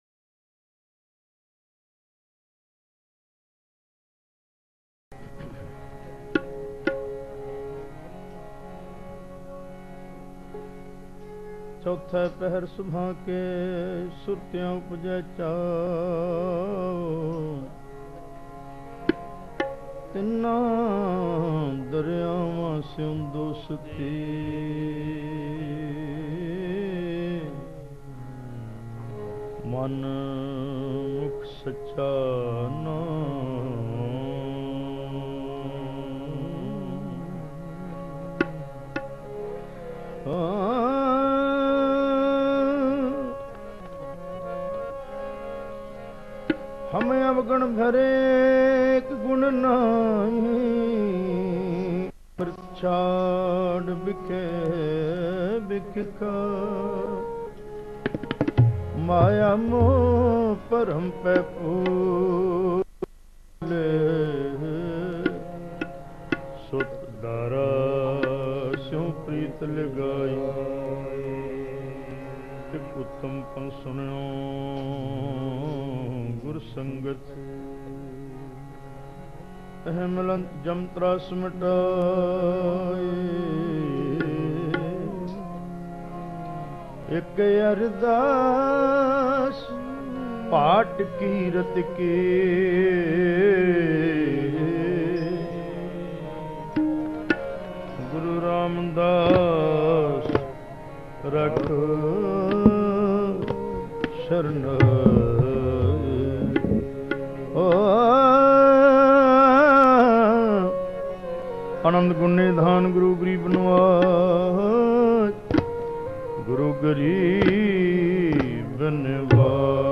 Genre: -Gurbani Ucharan